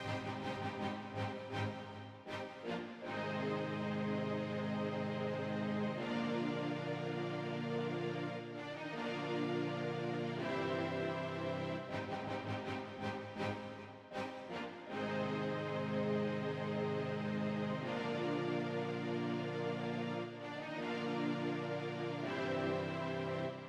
06 strings A.wav